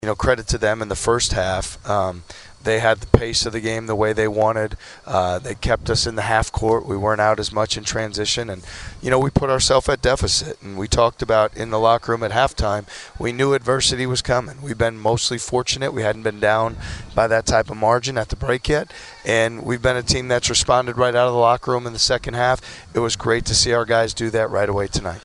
That’s ISU coach T.J. Otzelberger who says the Cyclones were more aggressive in the second half.